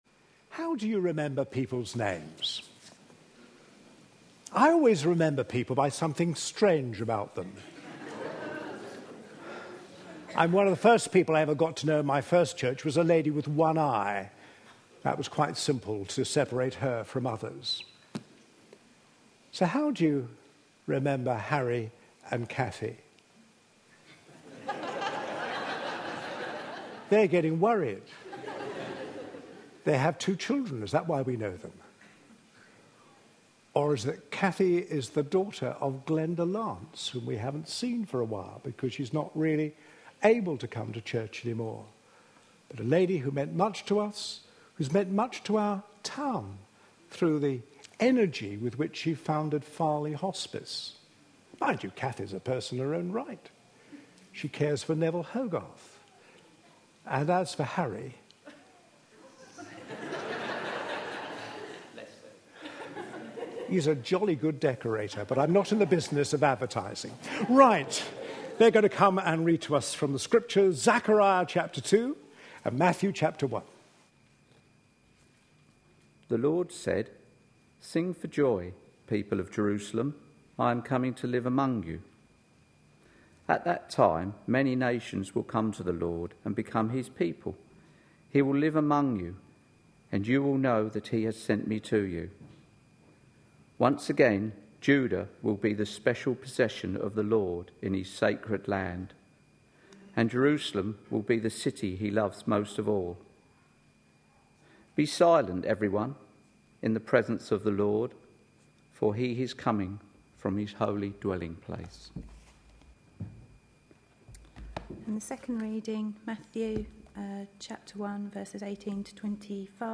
A sermon preached on 2nd December, 2012.